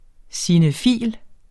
Udtale [ sinəˈfiˀl ]